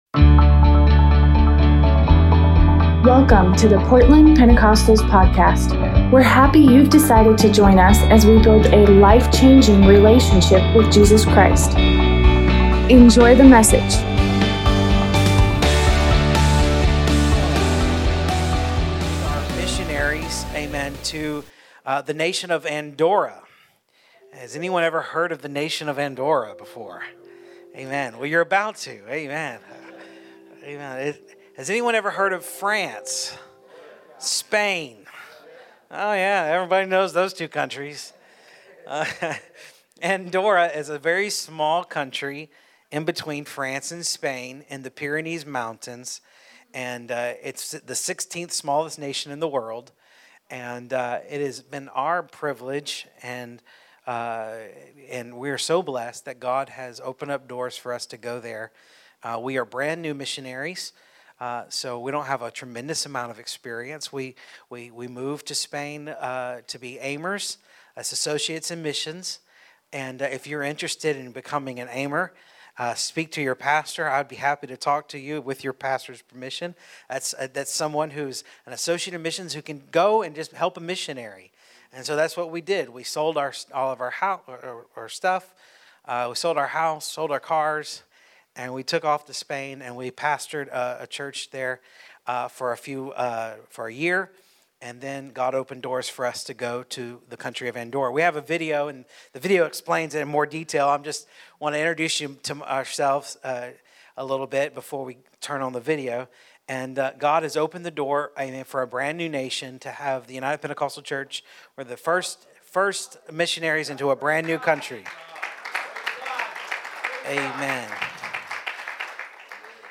Tuesday night sermon